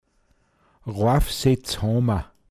pinzgauer mundart